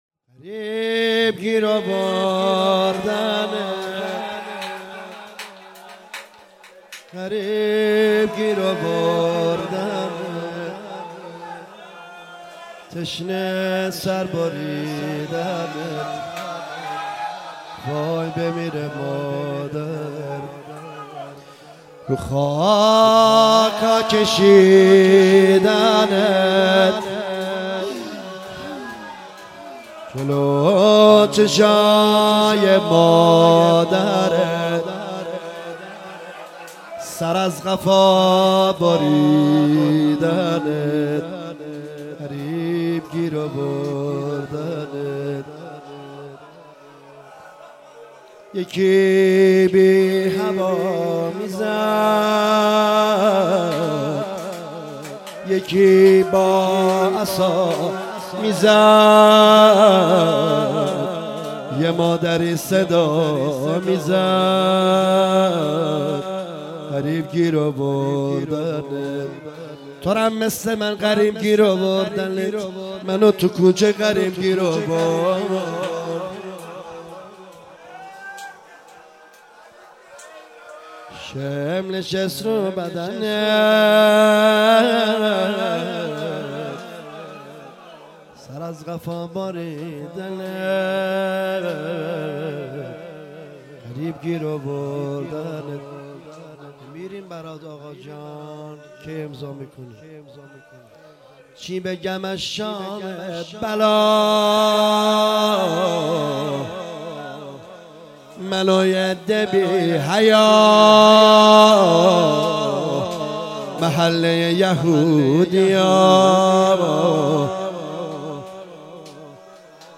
4 اسفند 96 - هیئت رزمندگان - روضه پایانی